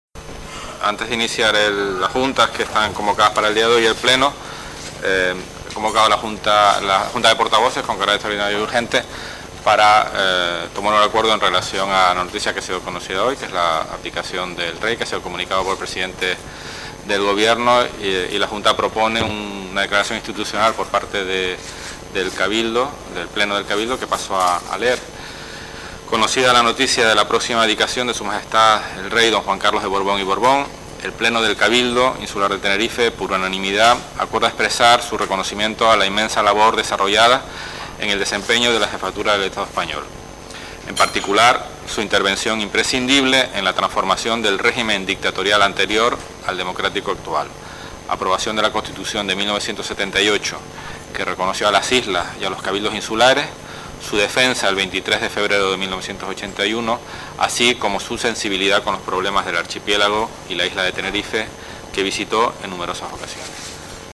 Declaración institucional por la abdicación del Rey Juan Carlos I